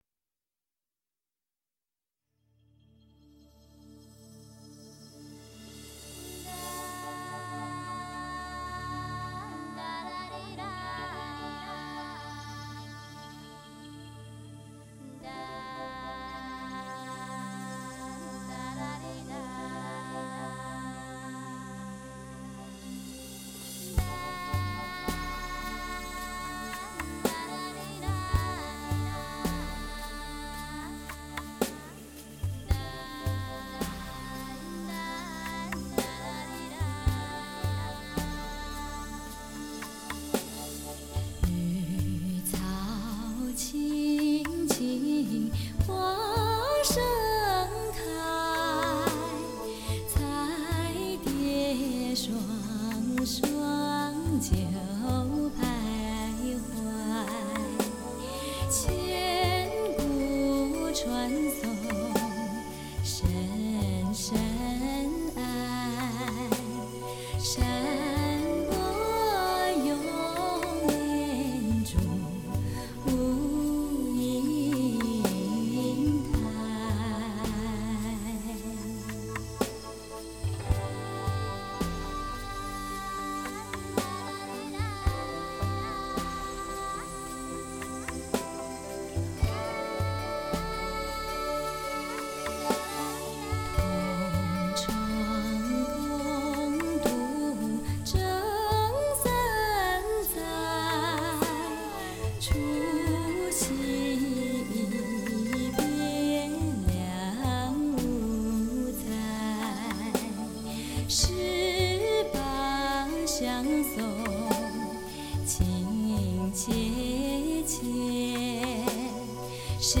情歌对唱，深情告白，畅快旋律，感人肺腑～
现代CD的极品是多声道环绕音乐。